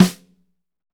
SNR P C S04R.wav